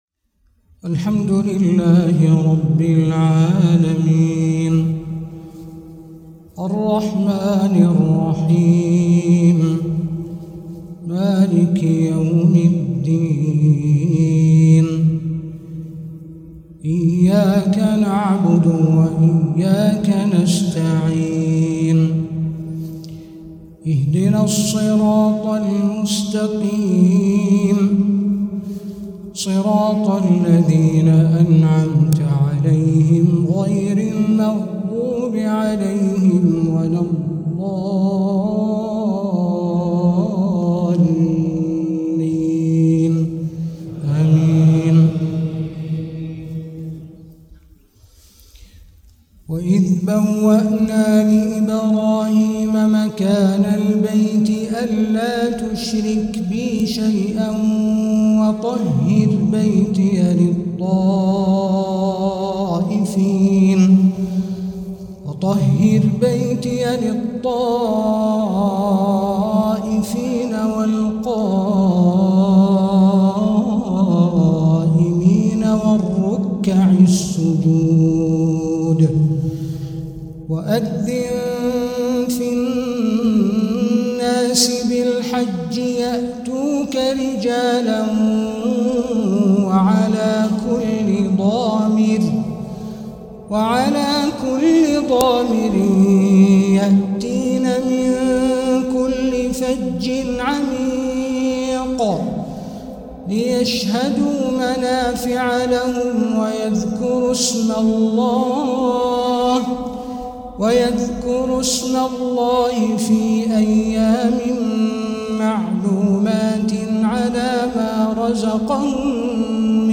تلاوات الفروض
من الأرض الطيبة مكة المكرمة
4. الدورة العلمية الأولى لأئمة الحرمين الشريفين في دورة الحج لعام 1446هـ